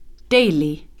Escolta com es pronuncia adjectiu